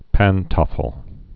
(păn-tŏfəl, -tōfəl, -tfəl, păntə-fəl)